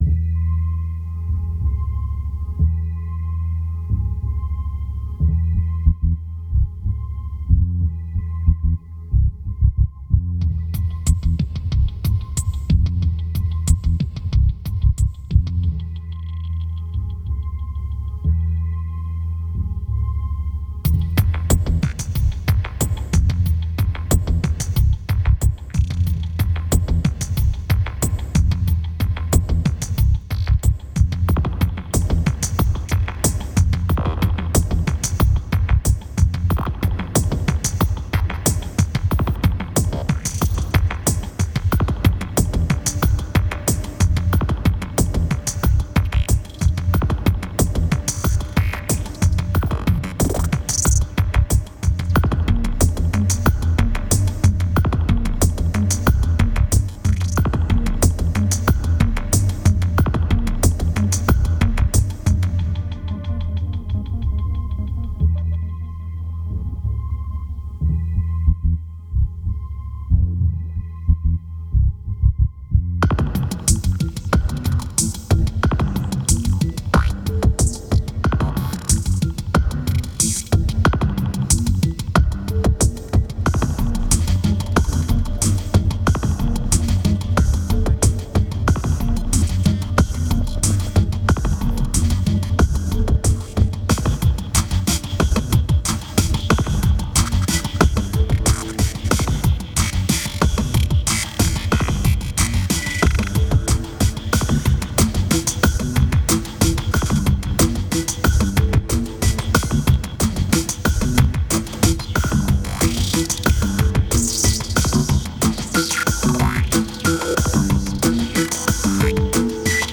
2224📈 - 6%🤔 - 184BPM🔊 - 2009-09-11📅 - -175🌟